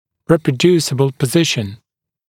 [ˌriːprə’djuːsɪbl pə’zɪʃn][ˌри:прэ’дйу:сибл пэ’зишн]воспроизводимое положение (напр. естественное положение головы при проведении рентгенографии для сохранения положения относительных линий)